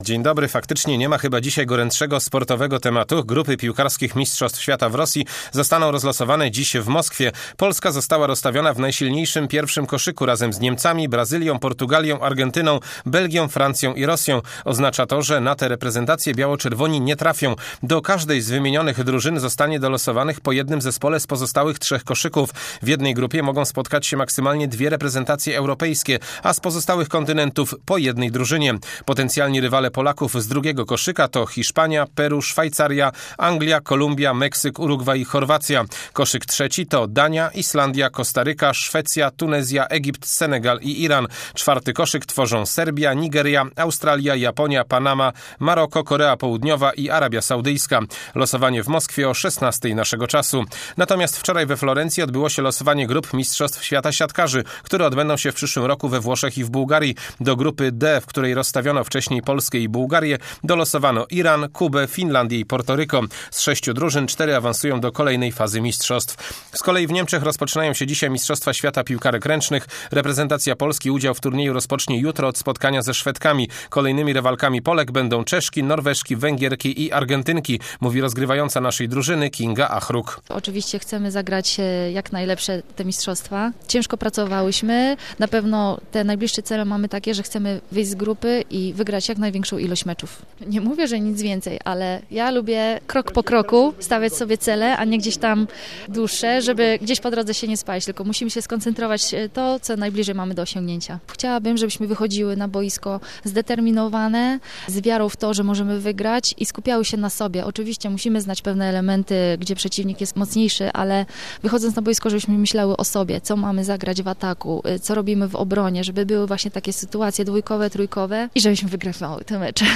01.12 serwis sportowy godz. 7:45